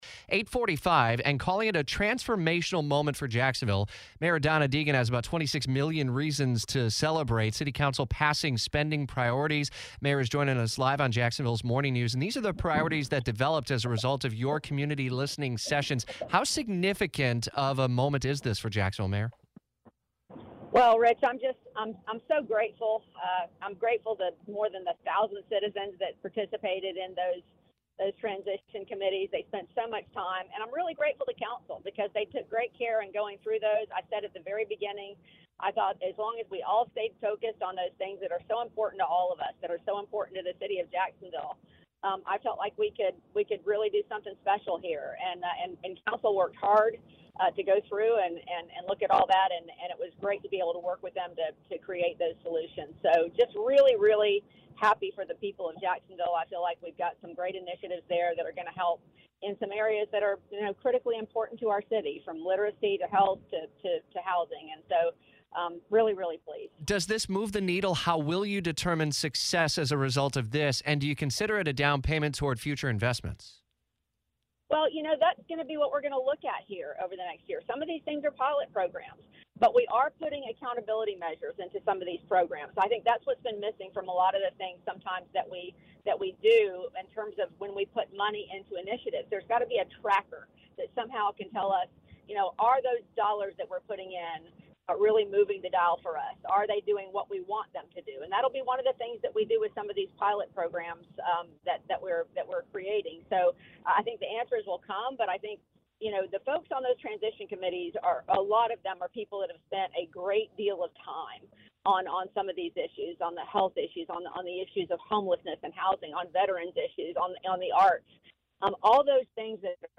Mayor Deegan reacts to council passage